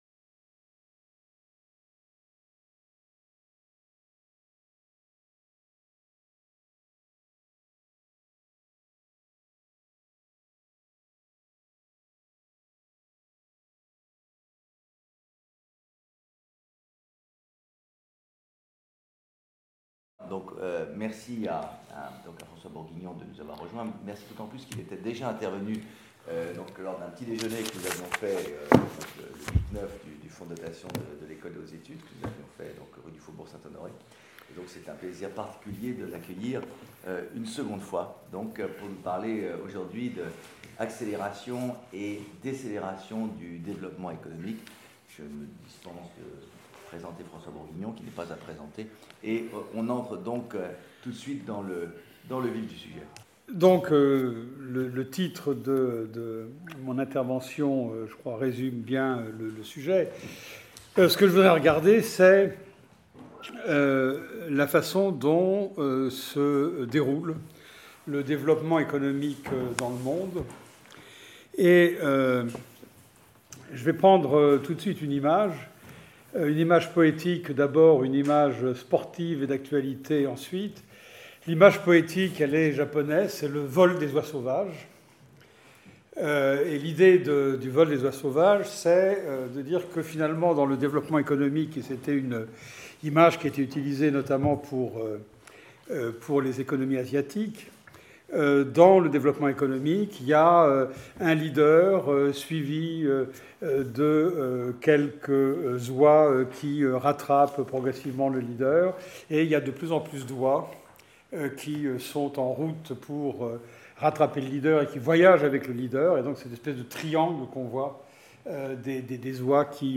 Séminaire dispensé par François Bourguignon Aujourd'hui, toutes les sociétés vivent sous l'effet de l'augmentation de la vitesse et de sa généralisation.